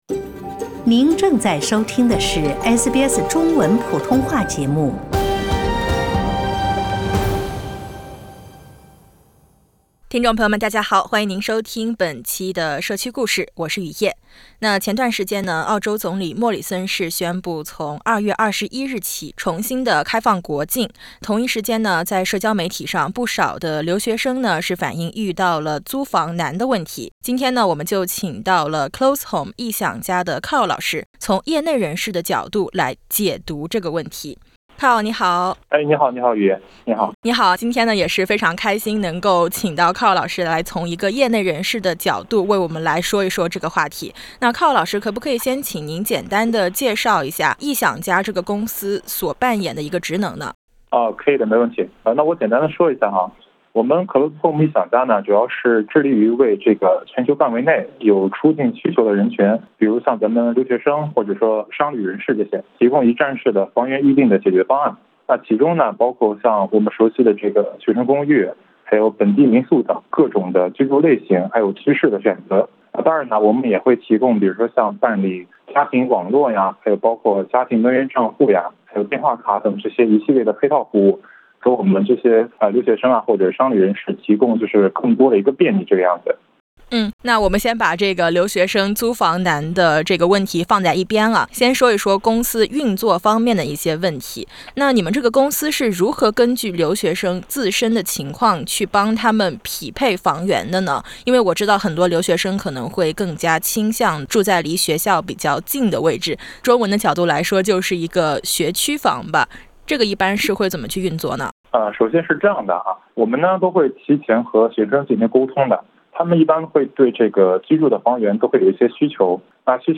SBS 普通话电台